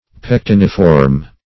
Pectiniform \Pec*tin"i*form\ (p[e^]k*t[i^]n"[i^]*f[^o]rm)